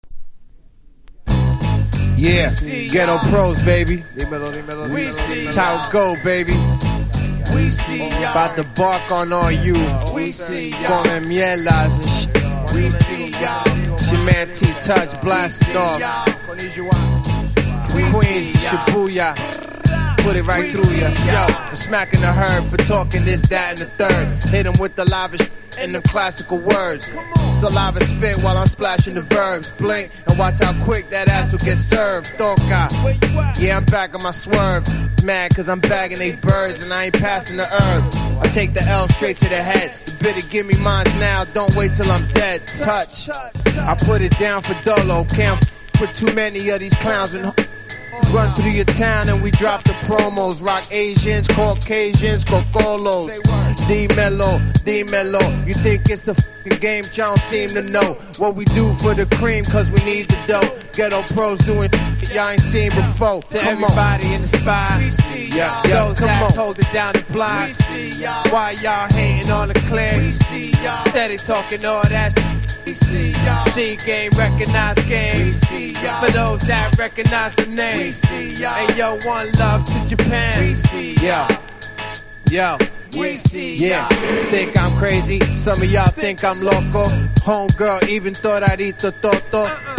ORIGINAL ( CLEAN )